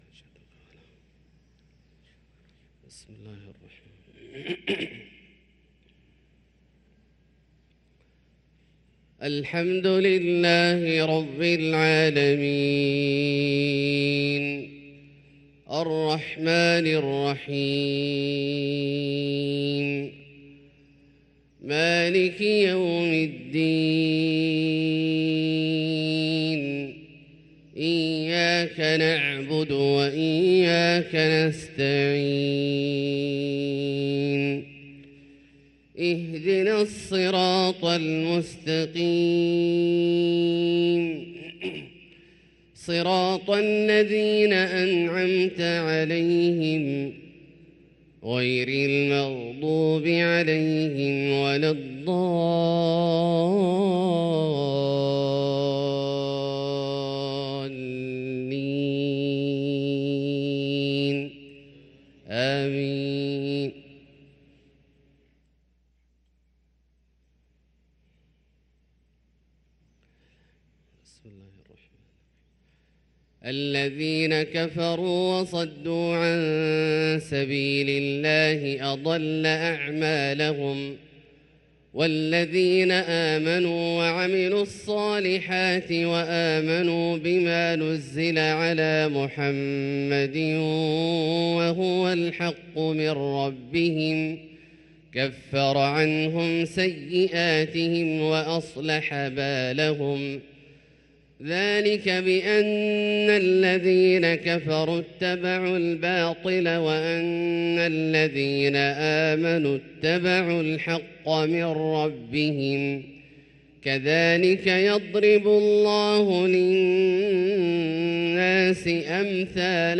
صلاة الفجر للقارئ عبدالله الجهني 30 ربيع الأول 1445 هـ
تِلَاوَات الْحَرَمَيْن .